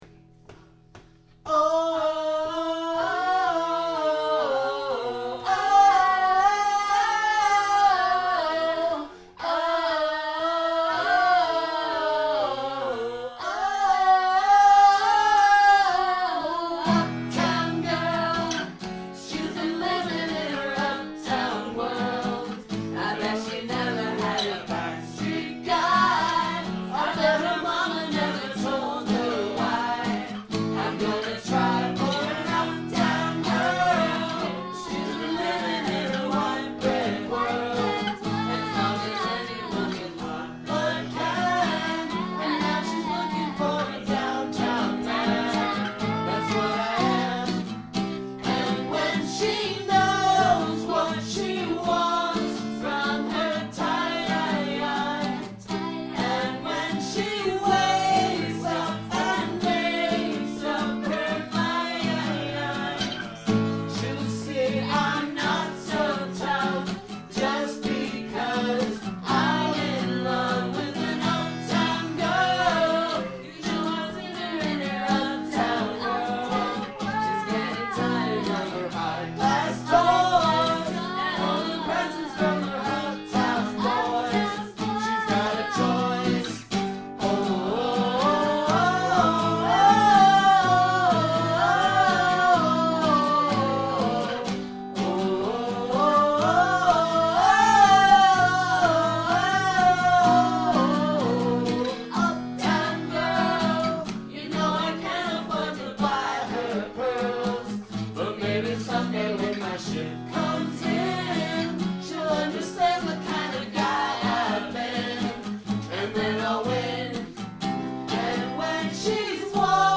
This ensemble approach is really unbelievable!